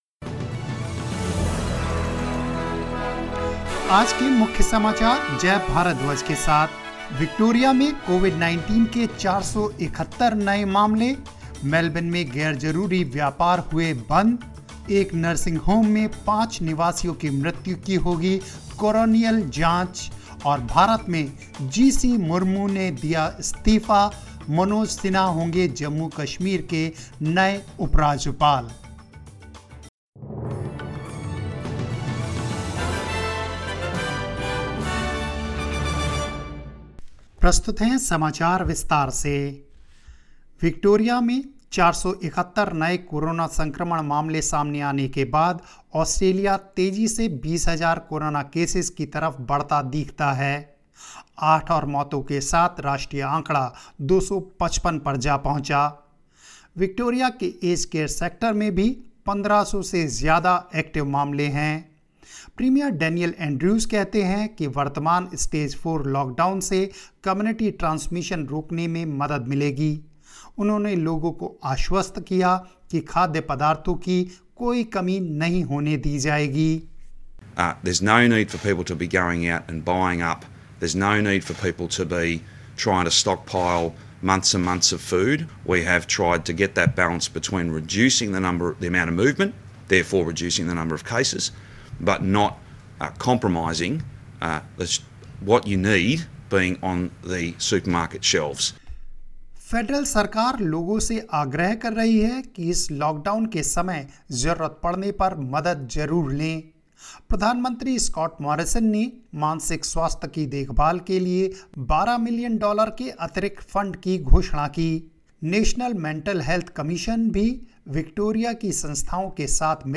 hindi_0608_news_and_headlines.mp3